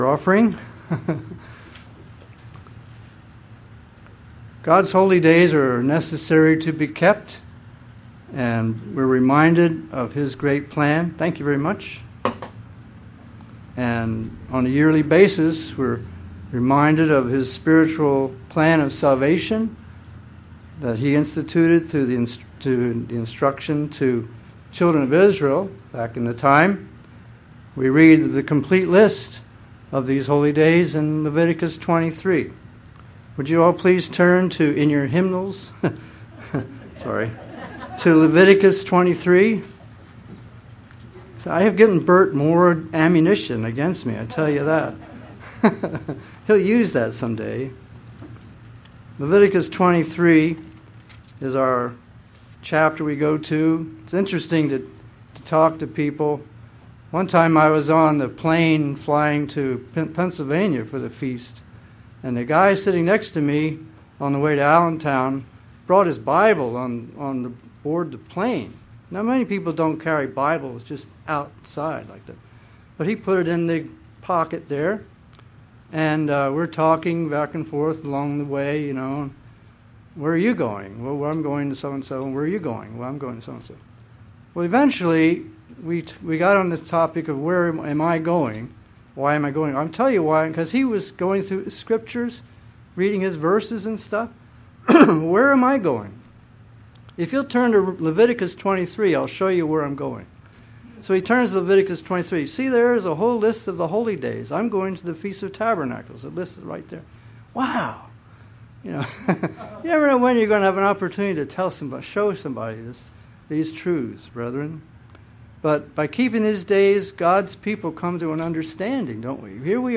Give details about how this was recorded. Given in Vero Beach, FL